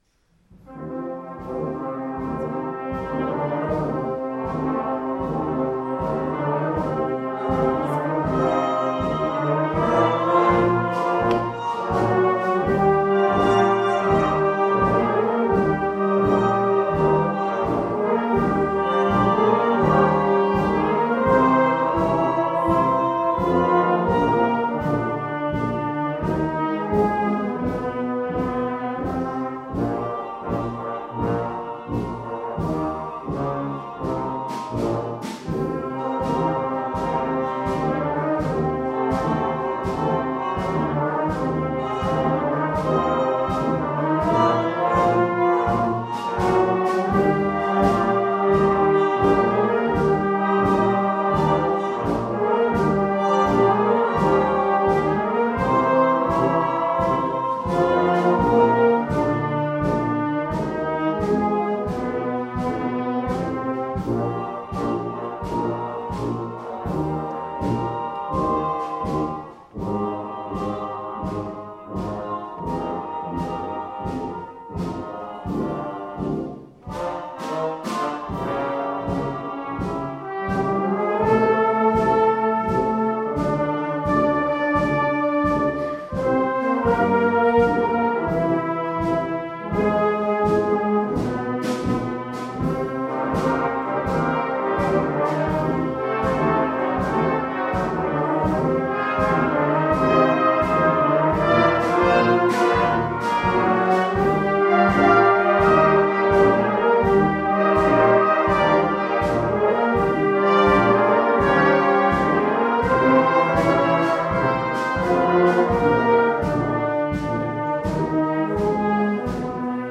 The following pieces were recorded at our concert at Foxearth Church on 19th September 2015